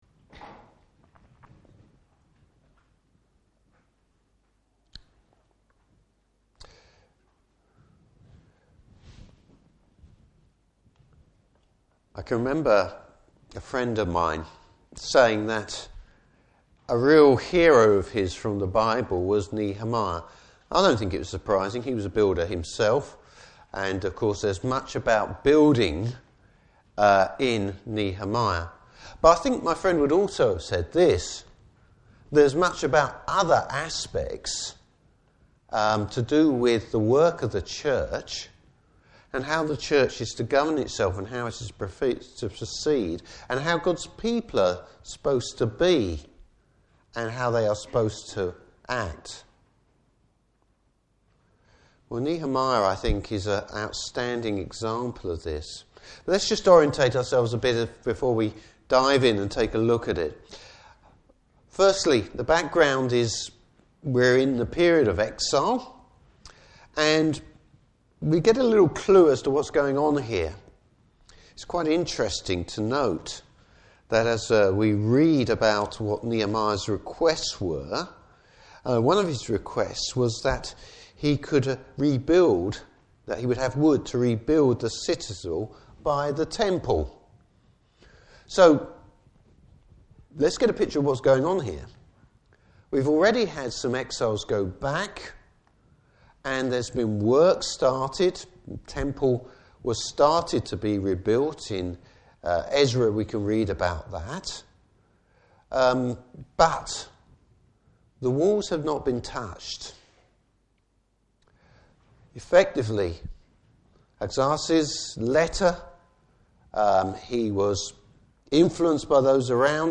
Service Type: Morning Service Repentance before petition when it comes to prayer.